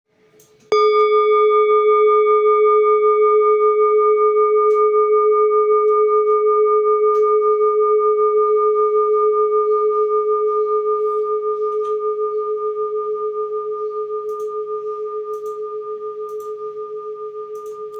Jambati Singing Bowl-30436
Jambati Singing BowlSinging Bowl, Buddhist Hand Beaten, Antique Finishing, Jhumkabati
Material Seven Bronze Metal
It can discharge an exceptionally low dependable tone.